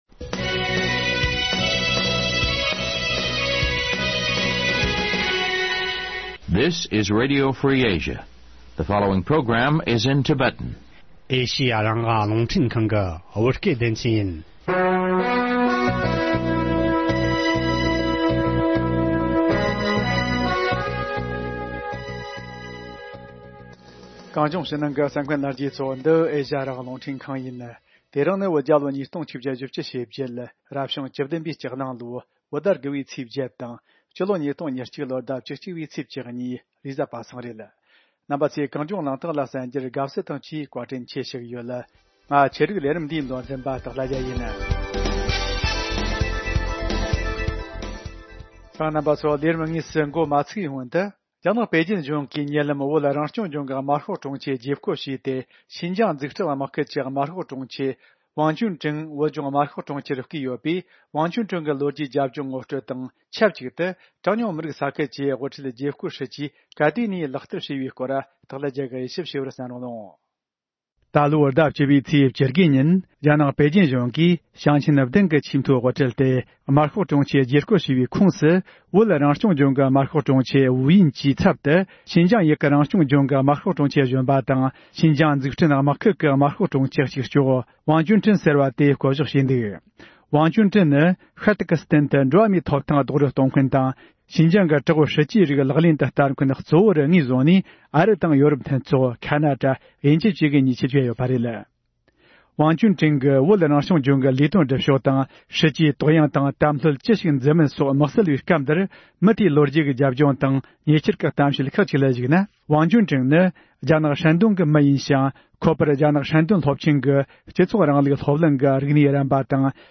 བགྲོ་གླེང་བྱེད་རྒྱུ་ཡིན།